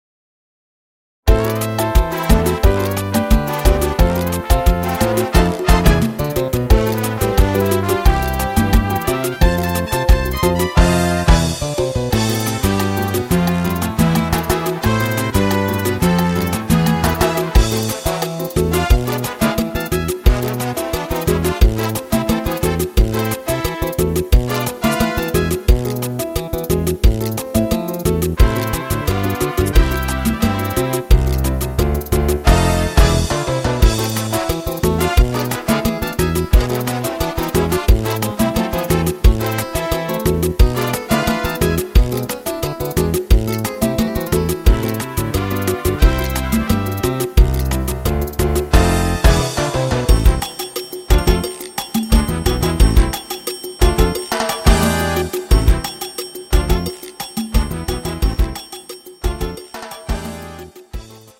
echter Salsa